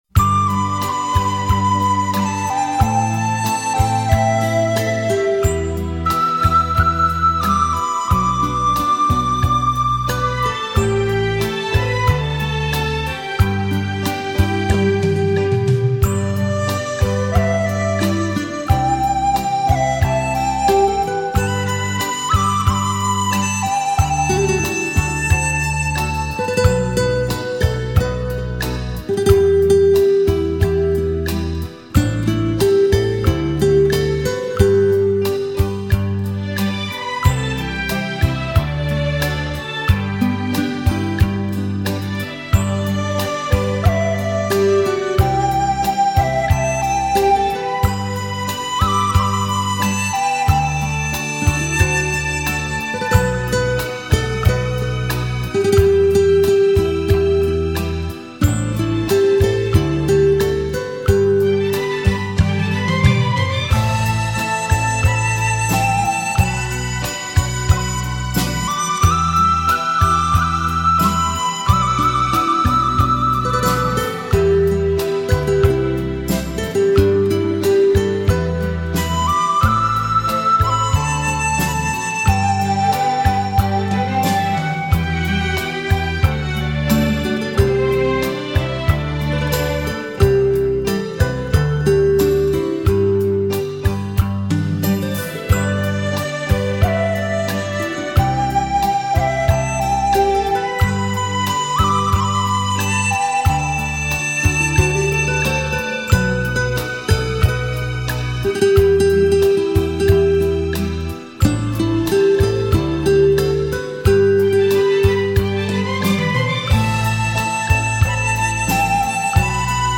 笛子